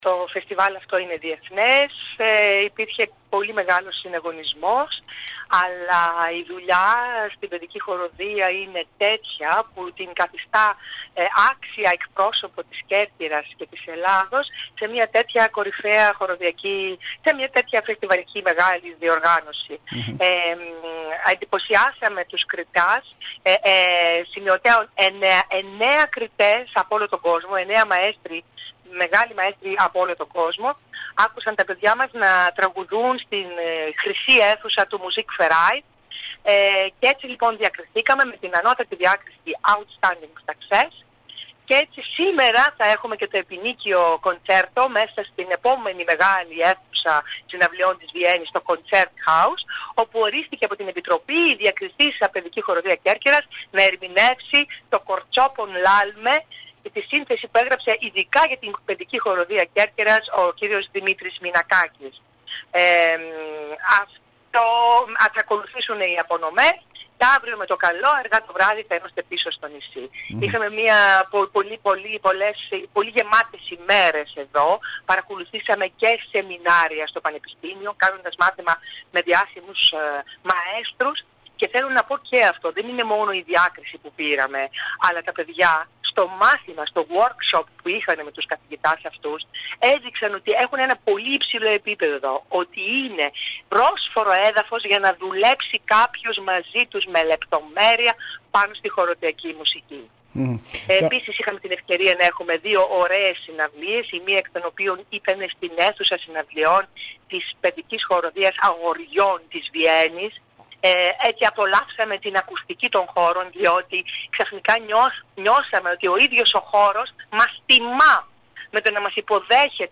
Περιφερειακοί σταθμοί ΚΕΡΚΥΡΑ